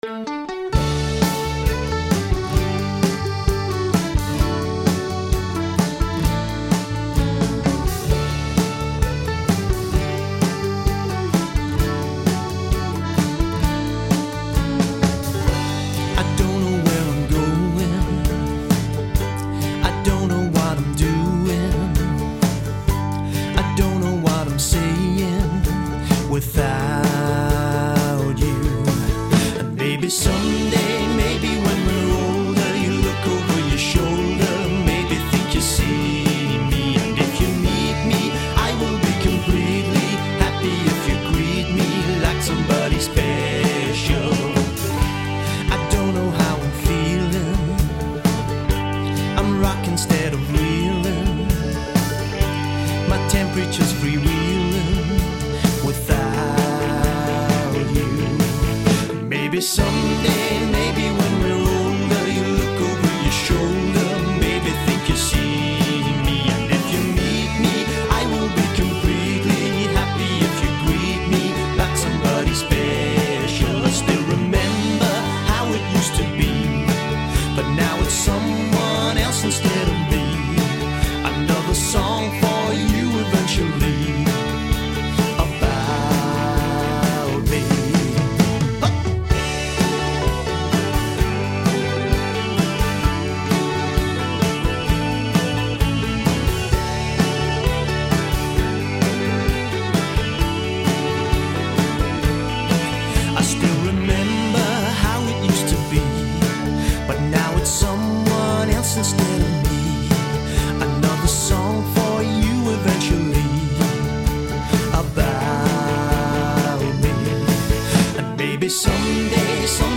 Bass and Lead Vocal
Drums
Guitar, Mandolin and backing vocals
Fiddle